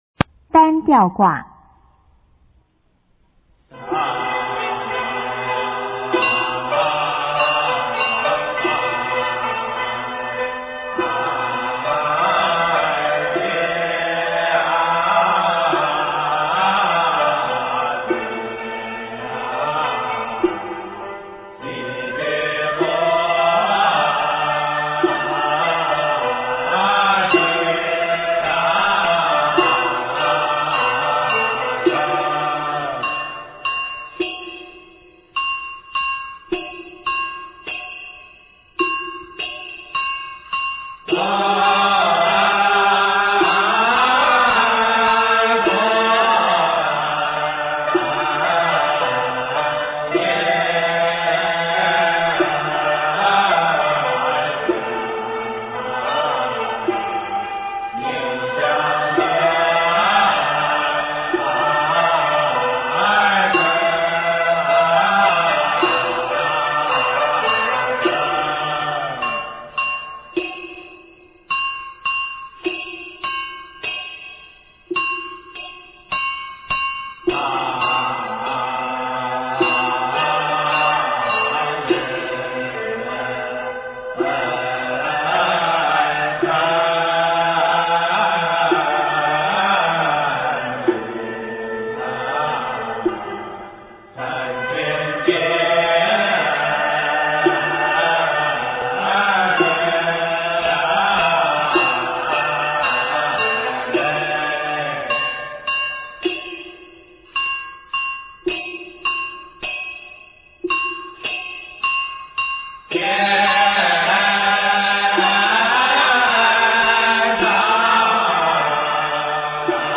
中国道教音乐 全真正韵 单吊挂 - 道音文化
其特点：围绕“商”音旋转，主腔在呈示后的展衍过程中，不再完整地重复或再现，主腔贯穿形态较隐蔽；其次是旋律中心音逐层转移，依次为“商、宫、羽、徽：宫。”在音乐渐进展开中，自第九小节后句幅缩短，气息细碎，节奏步步趋紧，音乐的展开性和不稳定性越益加强。乐汇、乐句多用共同音衍接(即“鱼咬尾”手法)。音高上形成缠联承递关系，如银线穿珠，句句紧扣，使全曲延绵不断，一气呵成。